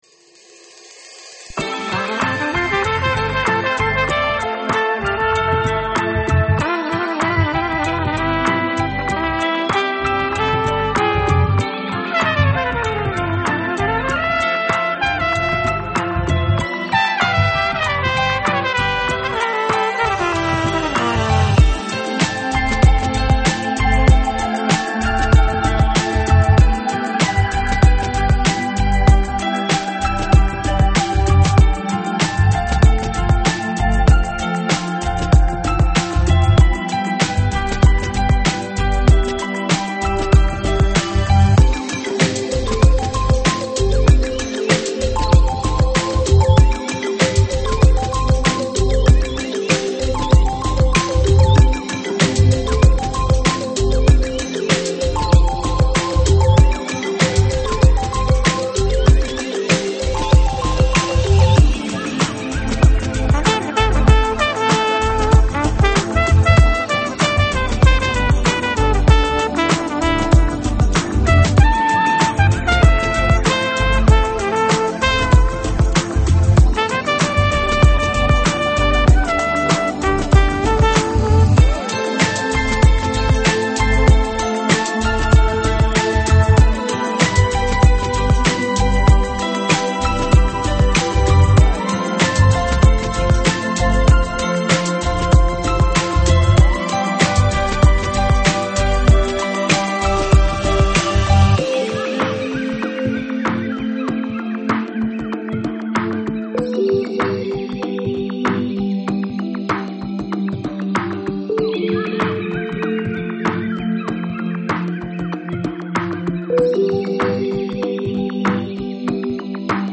Chill Out, Downtempo, Chill House, Deep House Носитель